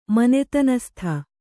♪ manetanastha